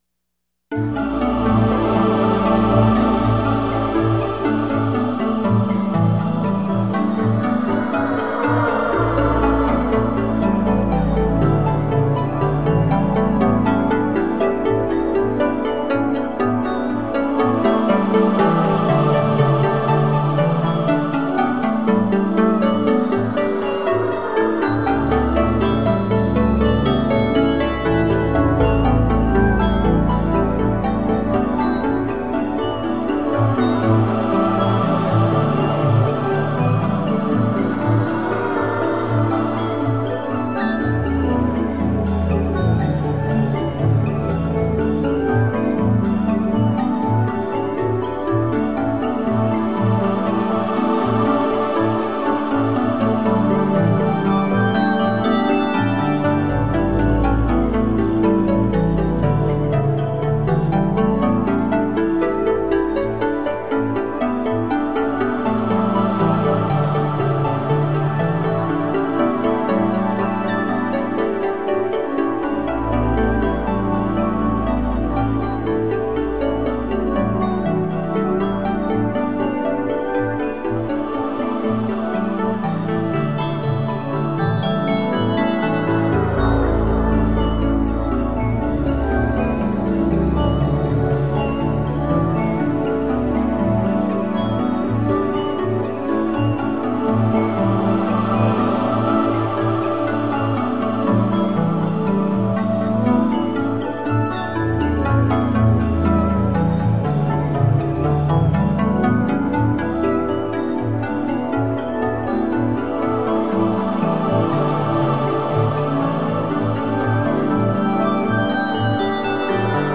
Beautiful ambient space music.
Tagged as: Ambient, New Age, Space Music